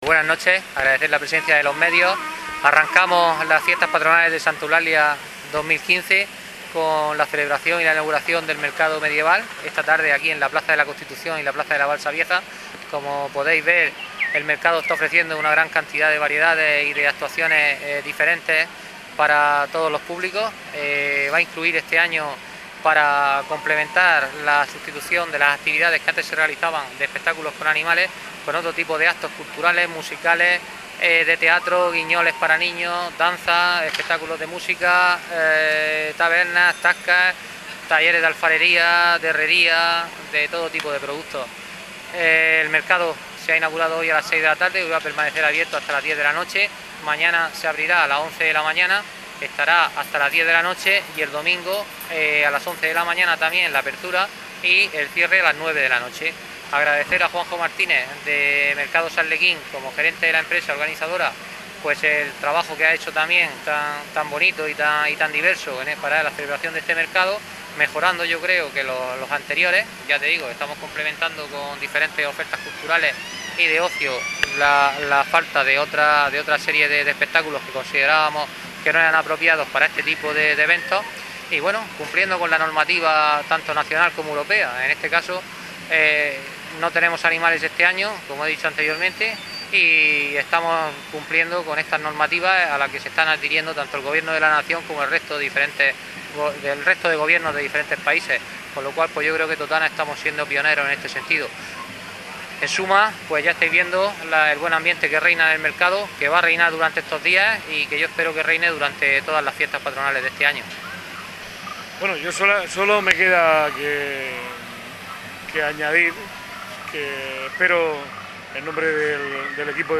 Mercado medieval - Fiestas de Santa Eulalia 2015
Durante todo el fin de semana los asistentes han podido disfrutar de las actividades programadas y ambientadas en la época medieval, en la plaza de la Constitución de Totana.